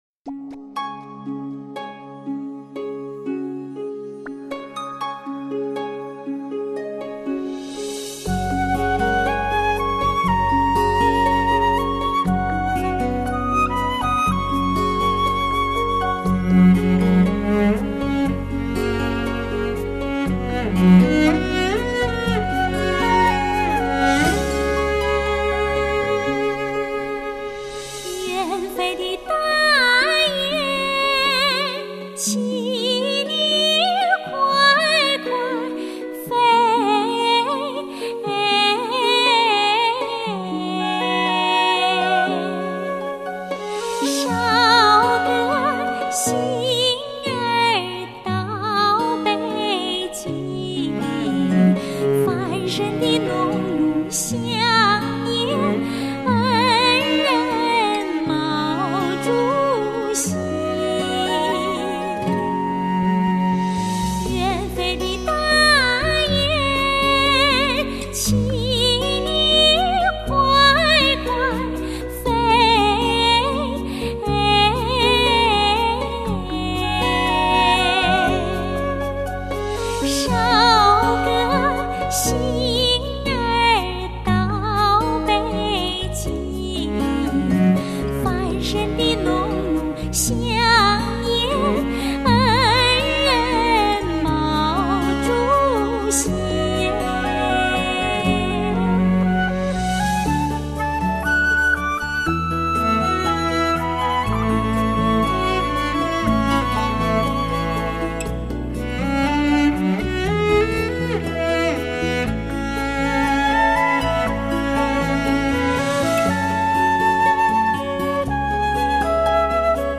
青年女高音歌唱家
擅长民族唱法